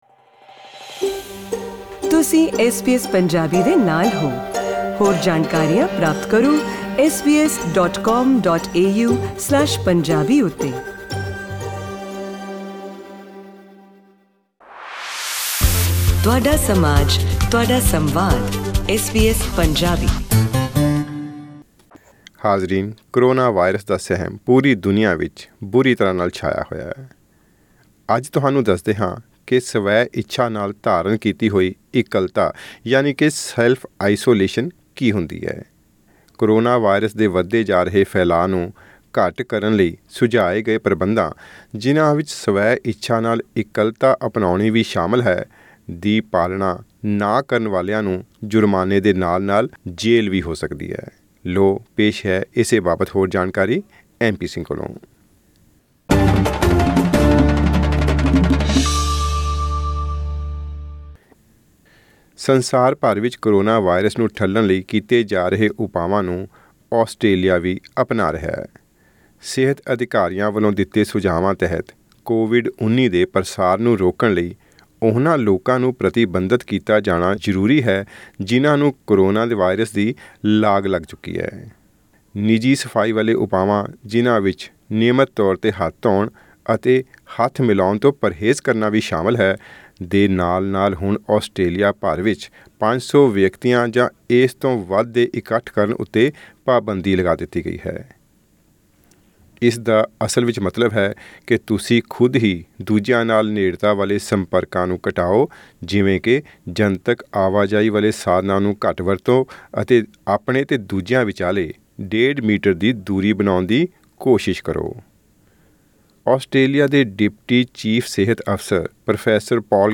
ਕਰੋਨਾਵਾਇਰਸ ਦੇ ਵਧ ਰਹੇ ਫੈਲਾਅ ਨੂੰ ਰੋਕਣ ਲਈ ਸੁਝਾਏ ਗਏ ਪ੍ਰਬੰਧਾਂ ਵਿੱਚ ਸਵੈਇੱਛਾ ਨਾਲ ਇਕੱਲਤਾ ਅਪਨਾਉਣੀ ਵੀ ਸ਼ਾਮਿਲ ਹੈ। ਇਸਦੀ ਪਾਲਣਾ ਨਾ ਕਰਨ ਵਾਲਿਆਂ ਨੂੰ ਵੱਡੇ ਜੁਰਮਾਨੇ ਦੇ ਨਾਲ਼-ਨਾਲ਼ ਜੇਲ੍ਹ ਵੀ ਹੋ ਸਕਦੀ ਹੈ। ਪੇਸ਼ ਹੈ ਇਸ ਸਬੰਧੀ ਇੱਕ ਵਿਸ਼ੇਸ਼ ਆਡੀਓ ਰਿਪੋਰਟ....